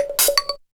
Rhythm_Loop_1_160.wav